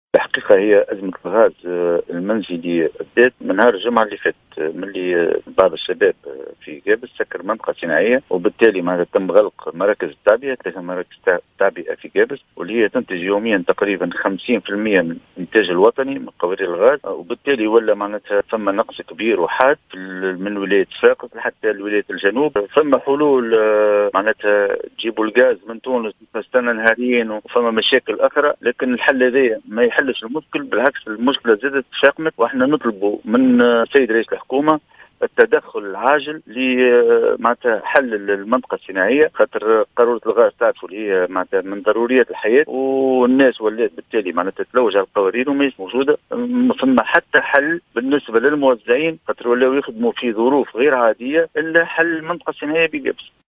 في تصريح للجوهرة أف أم